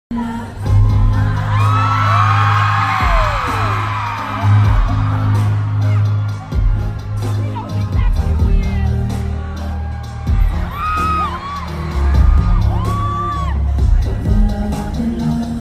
Concert Tour in Los Angeles